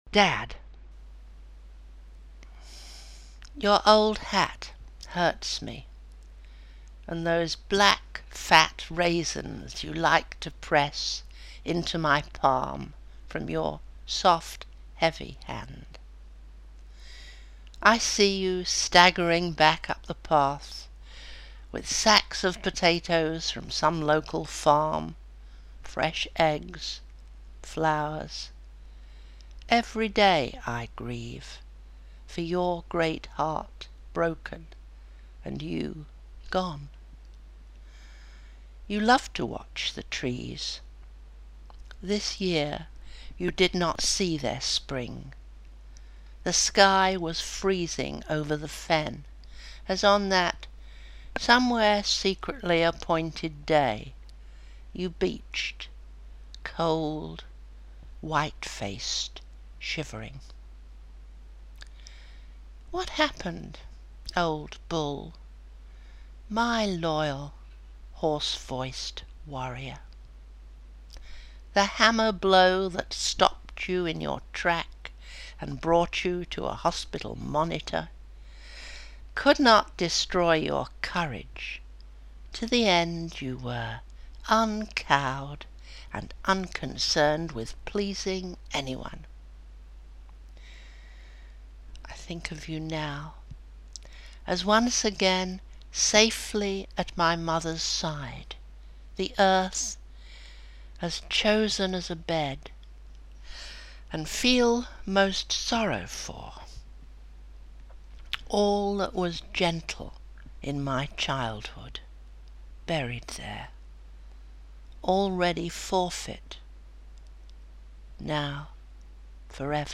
Elaine Feinstein reading her own poetry